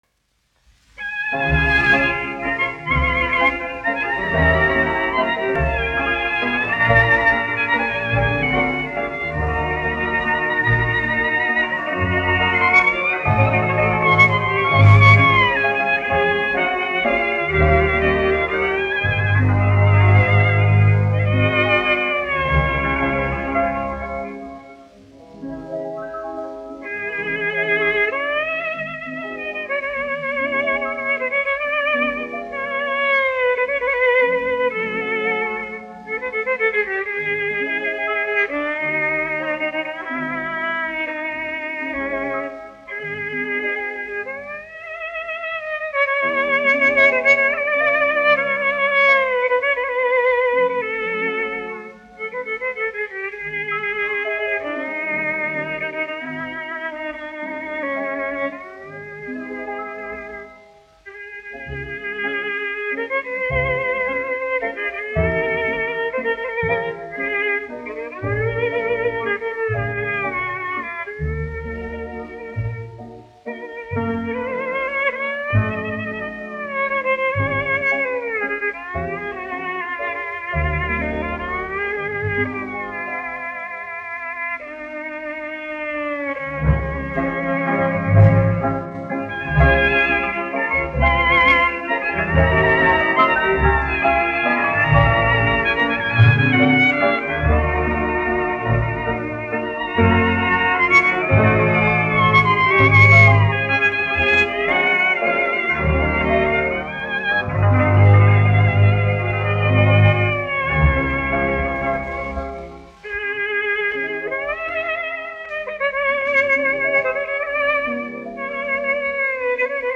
1 skpl. : analogs, 78 apgr/min, mono ; 25 cm
Populārā instrumentālā mūzika
Čigānu mūzika